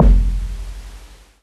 live_kick_9.wav